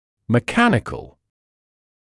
[mɪ’kænɪkl][ми’кэникл]механический; технический